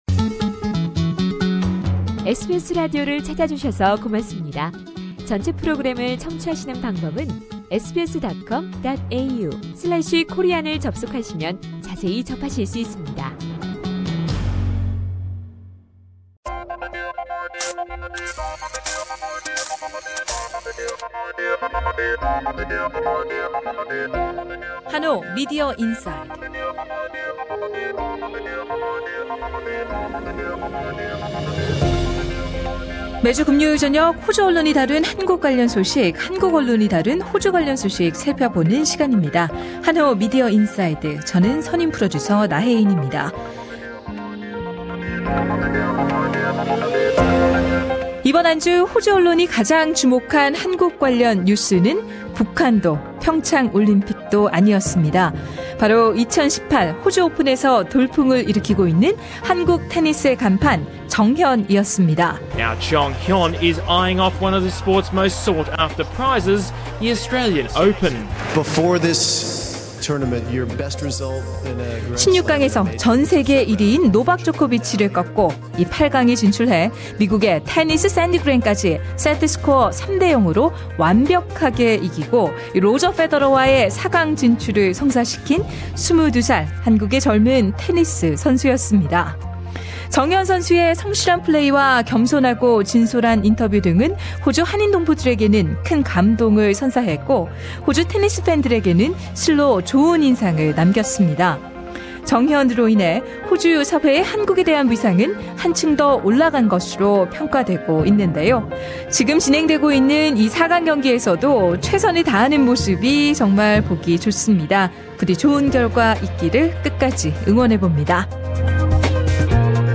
A full interview is available on podcast above.